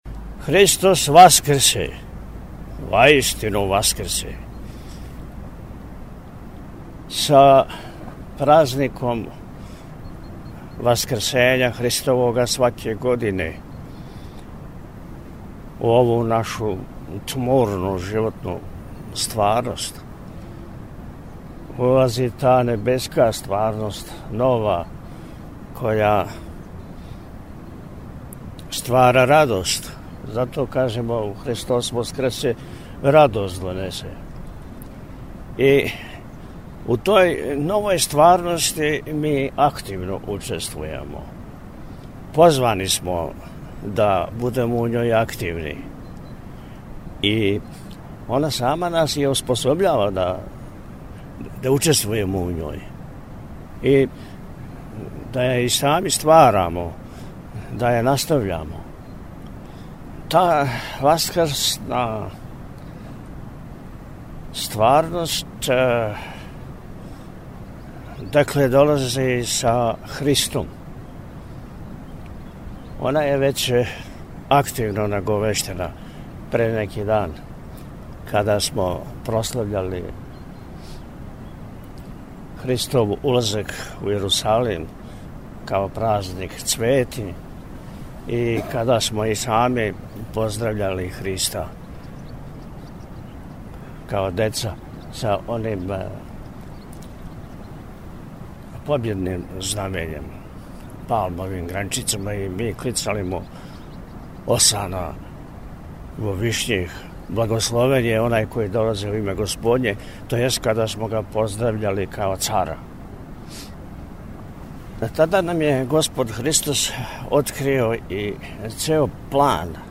На Велики Петак, 10. априла 2026. године, Његово Високопреосвештенство Архиепископ и Митрополит милешевски г. Атанасије, из порте манастира Милешеве, под крилима Белог Анђела, весника Васкрсења Христовог, упутио је Васкршњу поруку верном народу Епархије милешевске и свим људима добре воље.